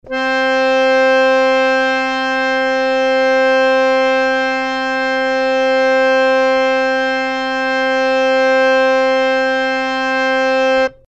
interactive-fretboard / samples / harmonium / C4.mp3
C4.mp3